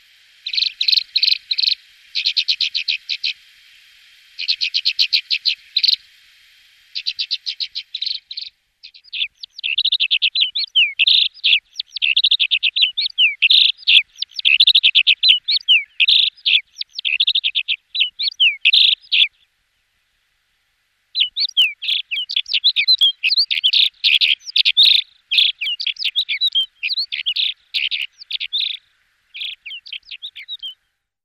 Płochacz halny - Prunella collaris
Śpiew płochacza to proste kilkusylabowe, trelujące gwizdy.
głosy